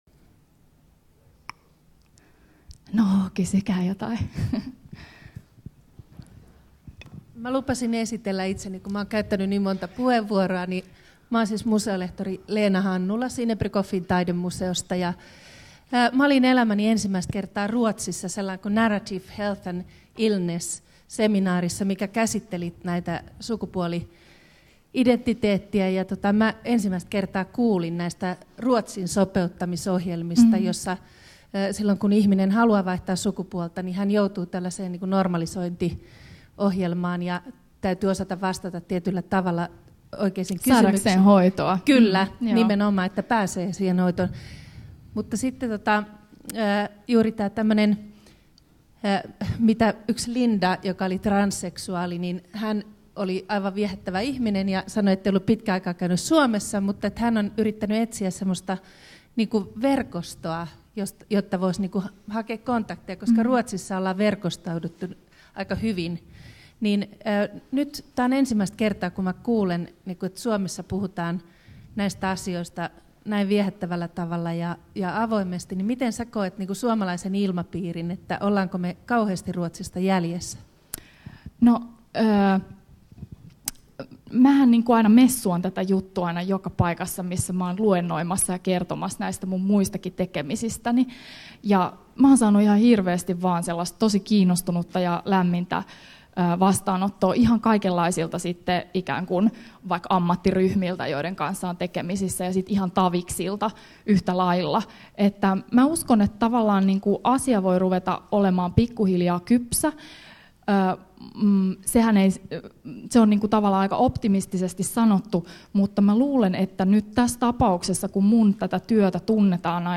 Keskustelu